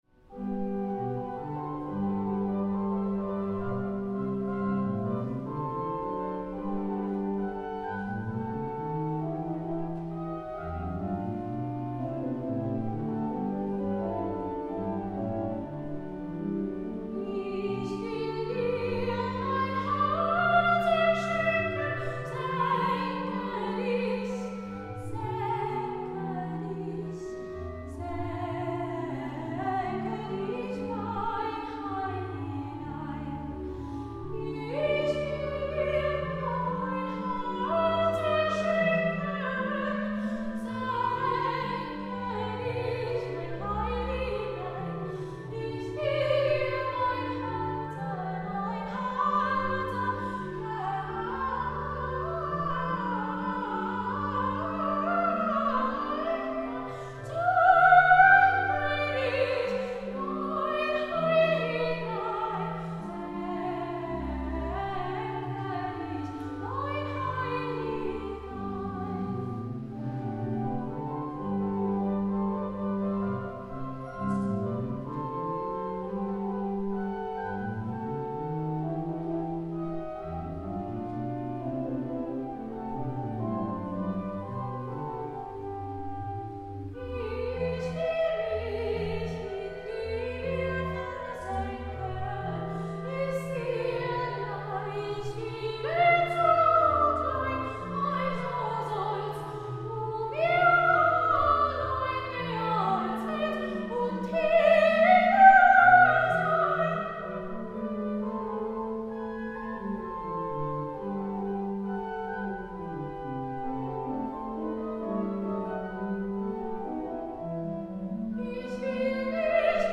Dezember, 2014, 10:00 Uhr, Martin-Luther-Kirche, Ulm
Singegottesdienst